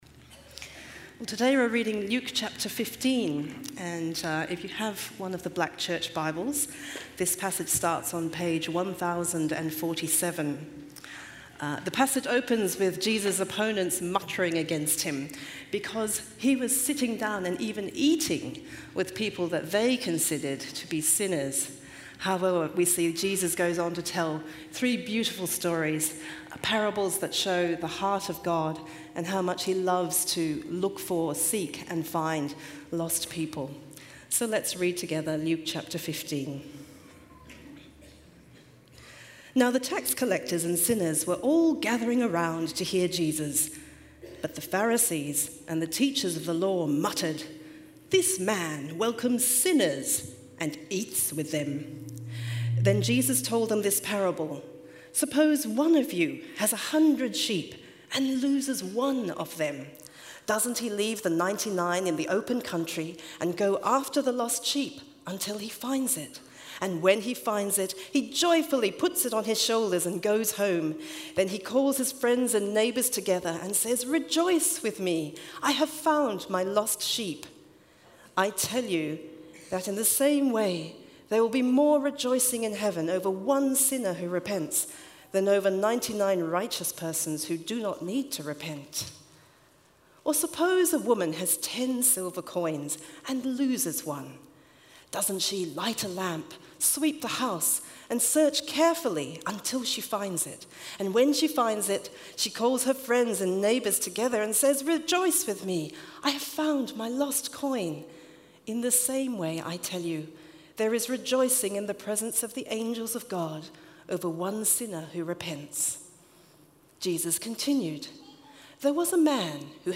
Talk 1 | Lost & Found | The Way Home Part 4 |Luke 15:1-31 - Living Church